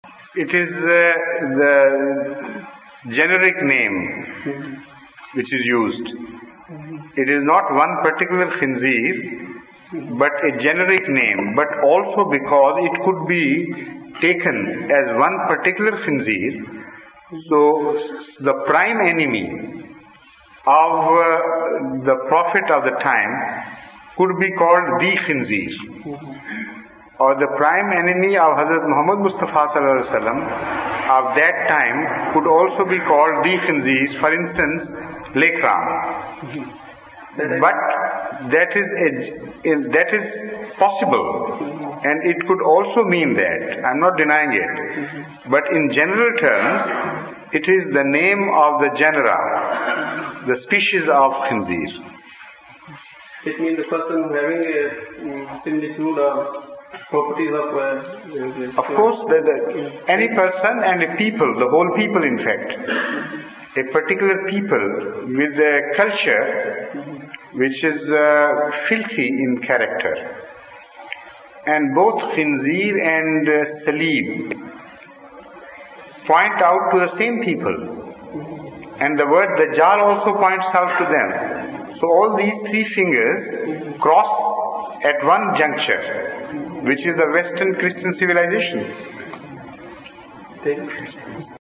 The London Mosque